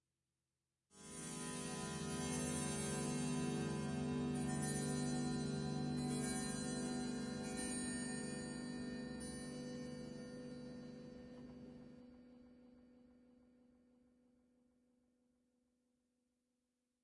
报时01小时12分圆润的
描述：壁炉钟敲12下。用Tascam DR1和Tascam TMST1话筒录制。醇化了，有一点回声，放慢了~50 Audacity 1.2 Beta (Unicode).
标签： 风铃 时钟
声道立体声